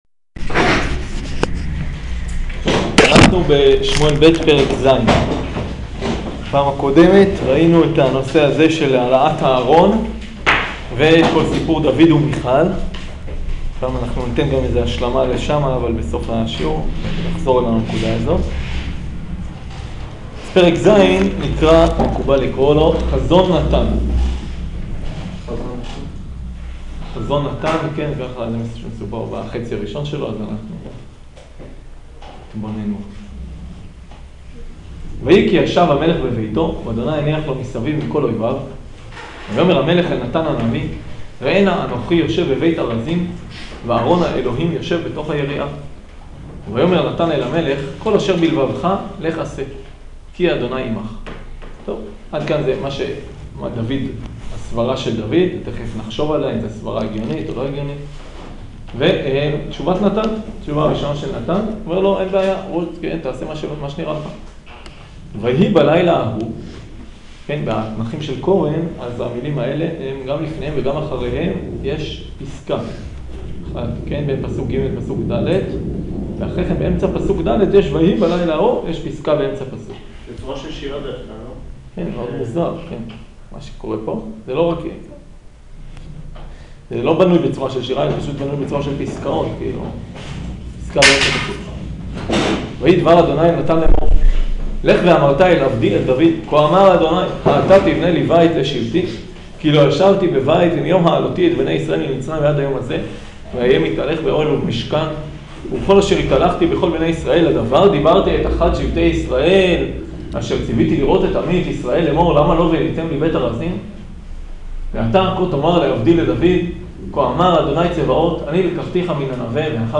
שיעור שמואל ב' פרק ז'